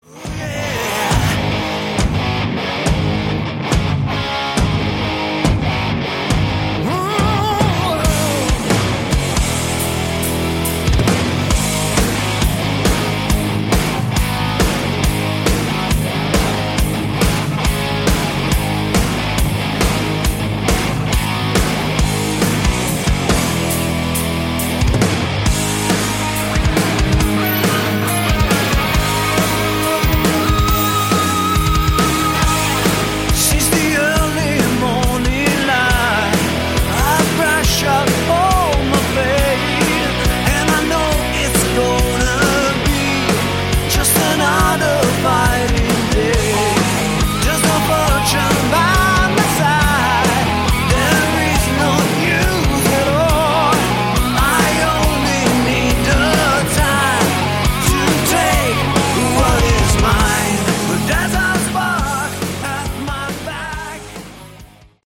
Category: Melodic Hard Rock
lead and backing vocals
rhythm guitars, lead and acoustic guitars
lead guitars, guitar solos
bass
drums